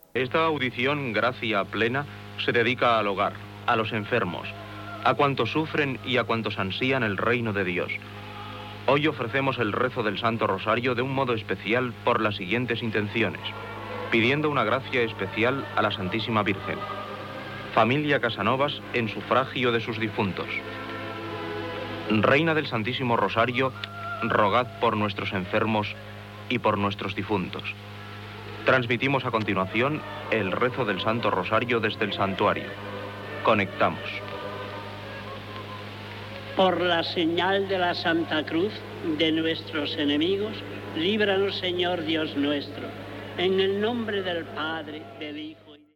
Presentació del res del Sant Rosari i transmissió del res des d'un santuari
Religió